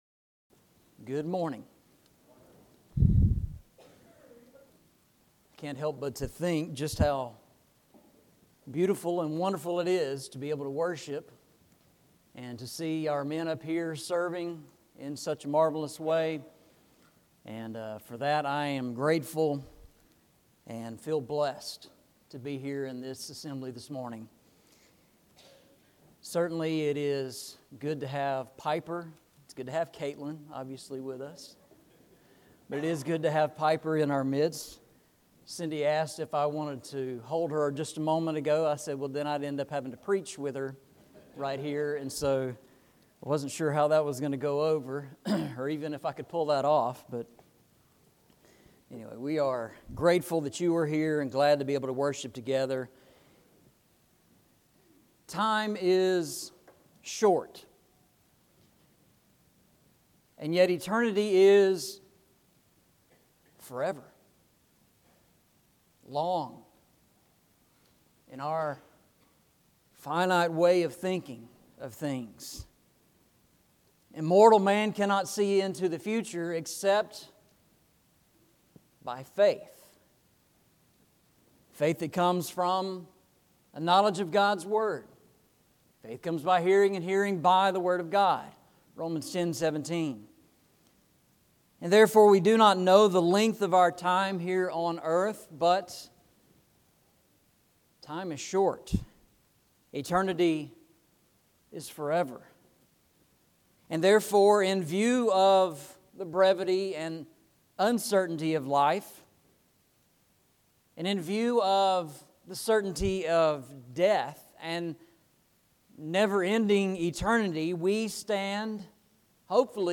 Eastside Sermons Passage: Colossians 1:27-29 Service Type: Sunday Morning « The Throne of God A Word Study